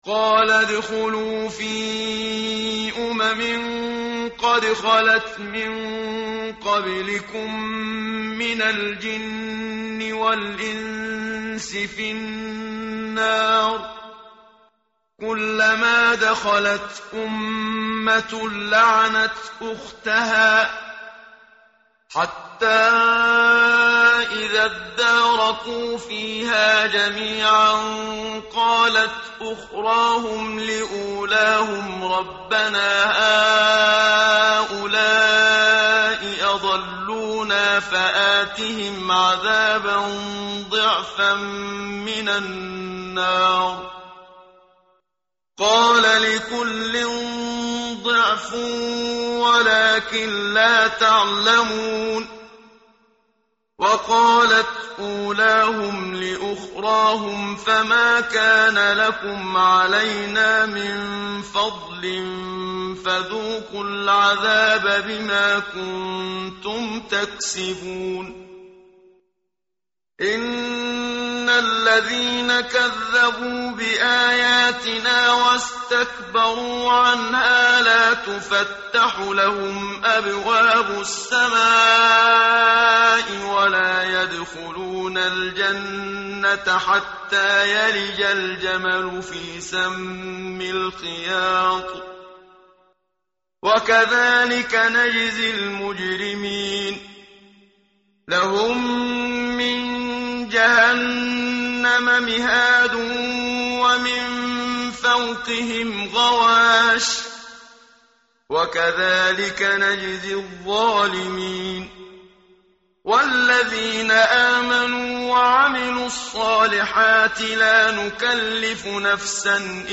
متن قرآن همراه باتلاوت قرآن و ترجمه
tartil_menshavi_page_155.mp3